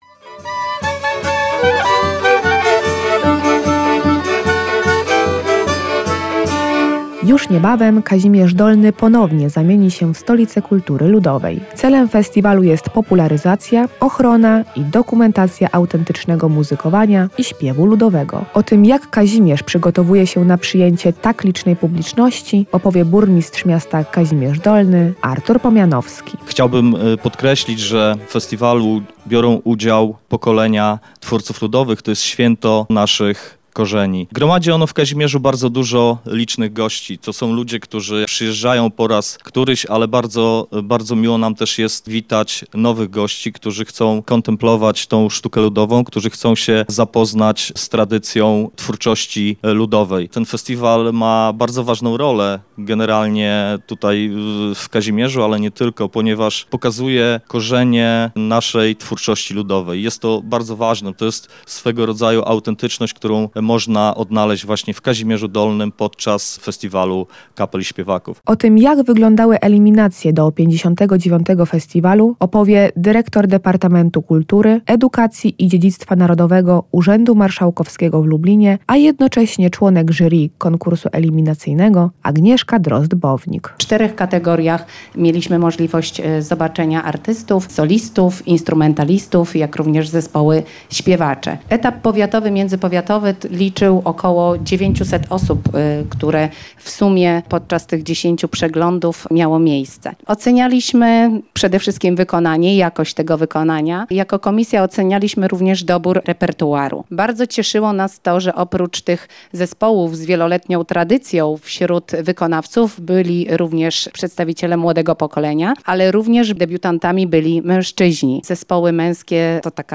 Celem festiwalu jest popularyzacja, ochrona i dokumentacja autentycznego muzykowania i śpiewu ludowego. O tym jak Kazimierz przygotowuje się na przyjęcie tak licznej publiczności opowie jego burmistrz Artur Pomianowski.